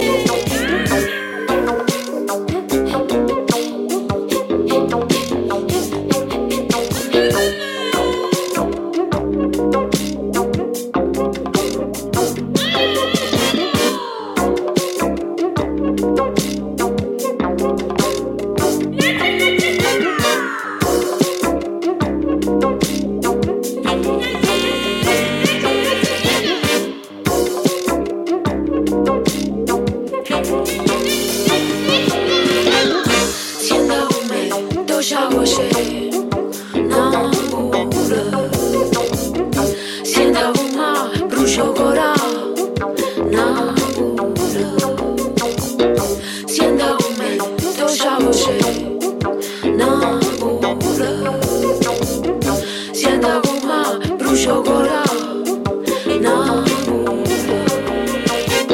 disco, jazz-funk, African rhythms